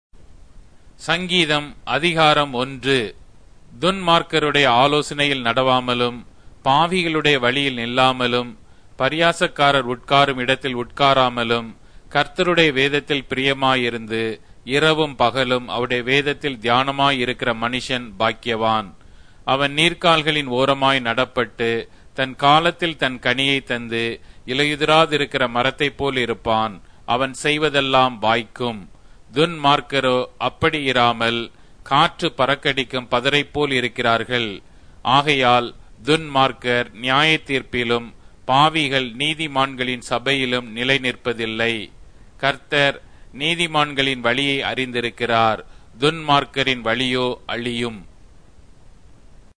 Tamil Audio Bible - Psalms 19 in Ervhi bible version